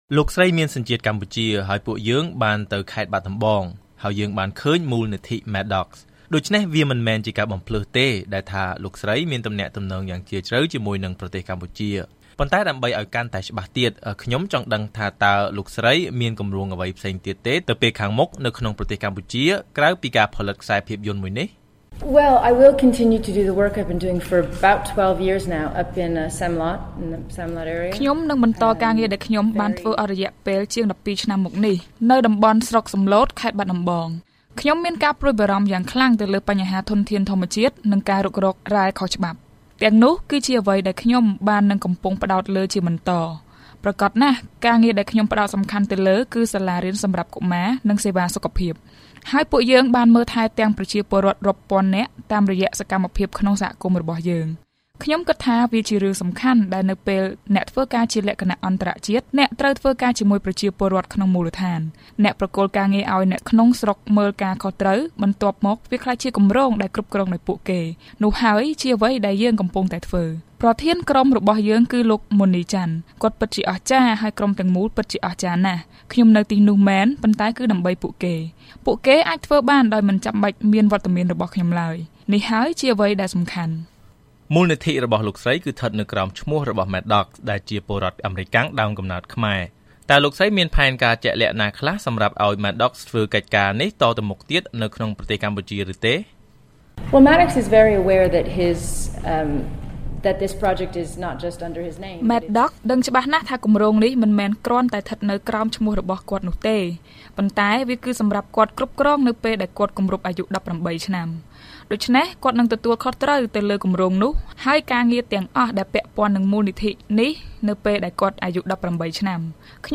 បទសម្ភាសន៍ផ្តាច់មុខ៖ ក្រោយការបញ្ចាំងភាពយន្ត លោកស្រី Jolie គ្រោងនឹងបន្តលើកស្ទួយជីវភាពប្រជាជនកម្ពុជា